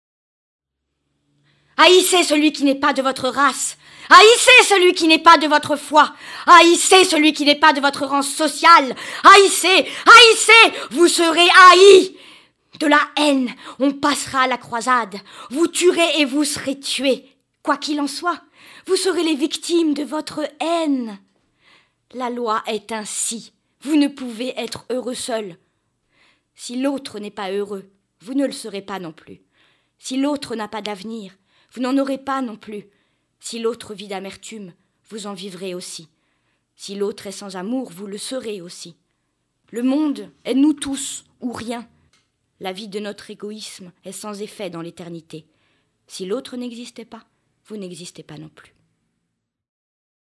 Sprecherin französisch.
Sprechprobe: Sonstiges (Muttersprache):